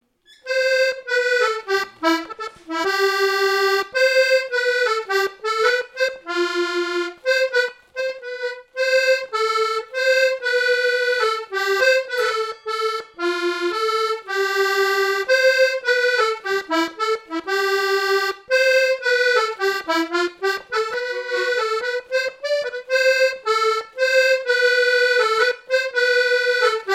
Rochetrejoux
danse : valse
Répertoire à l'accordéon diatonique
Pièce musicale inédite